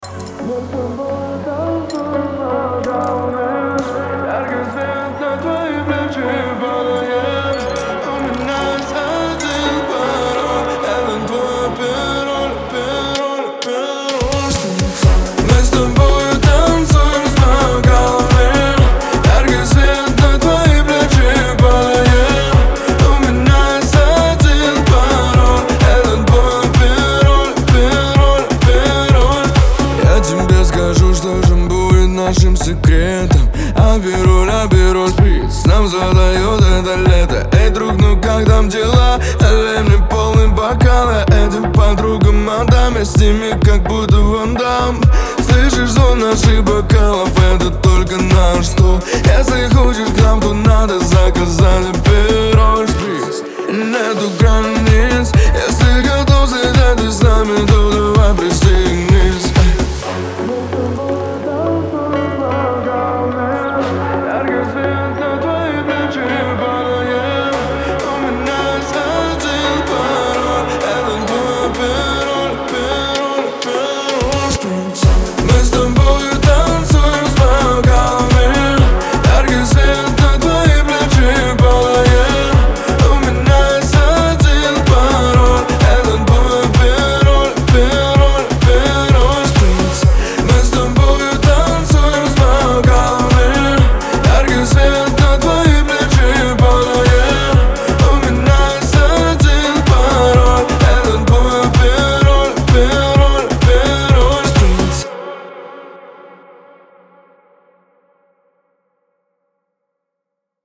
яркая и заводная песня